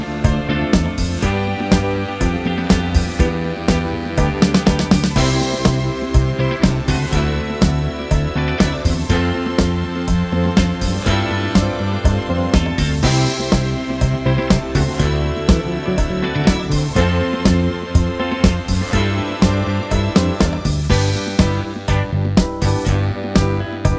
no Backing Vocals Disco 3:05 Buy £1.50